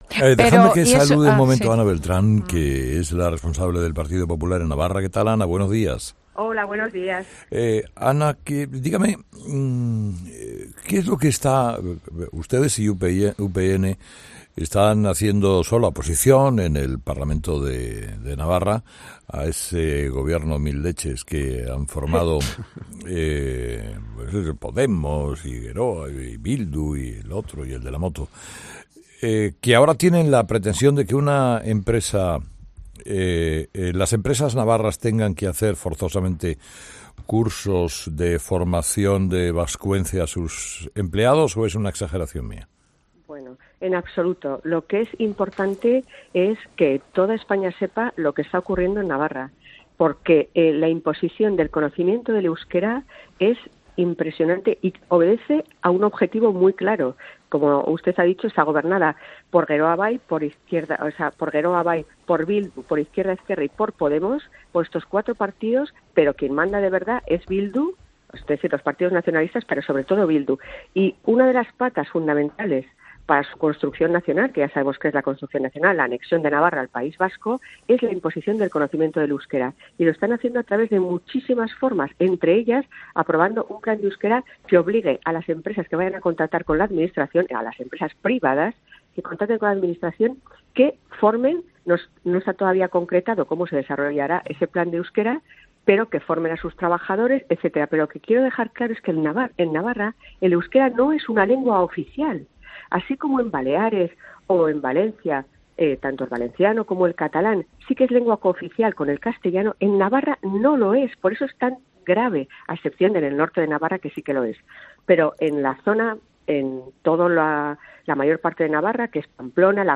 Entrevista a Ana Beltrán, líder del Partido Popular en Navarra